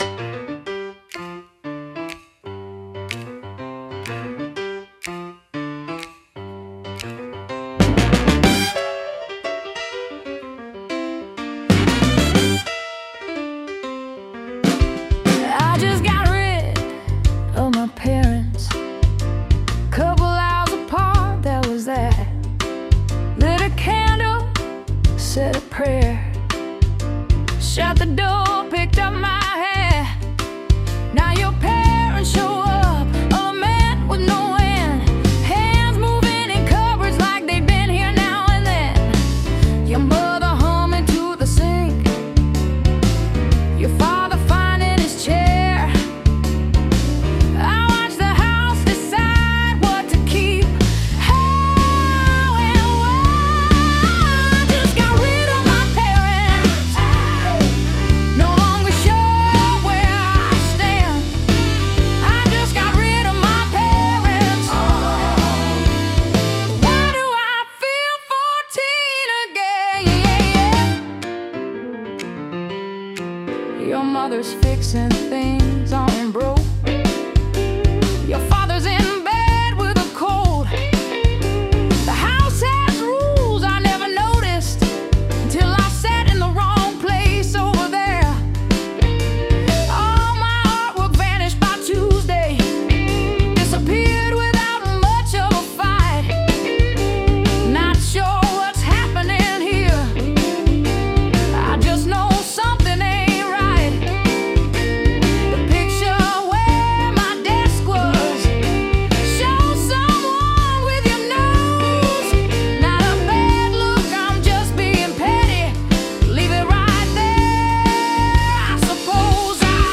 Allegorical · Playful